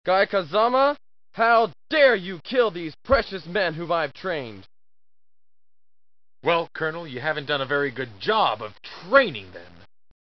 More of the worst voice acting you may ever hear Umm, "Stingy" isn't pronounced "Sting-y"
Umm, "Stingy" isn't pronounced "Sting-y"